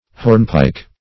\Horn"pike`\